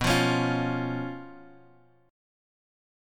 B Suspended 2nd Flat 5th